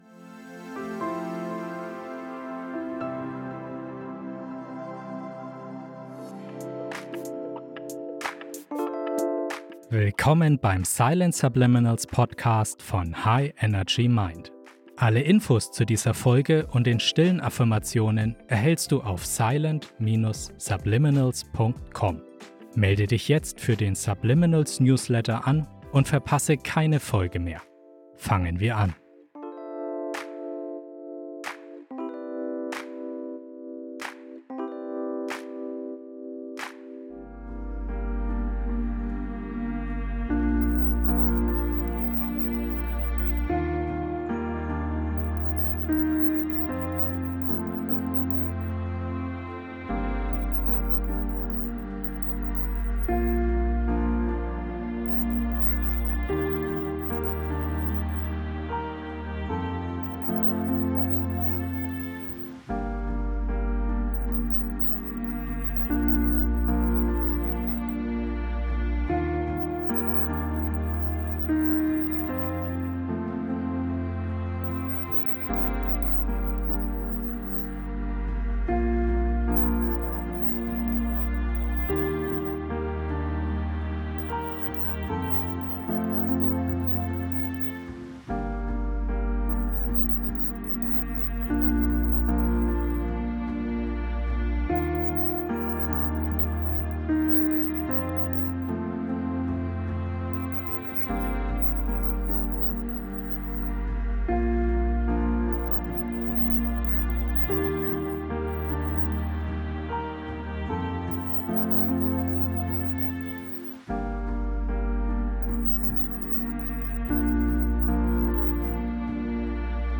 432 Hz Entspannungsmusik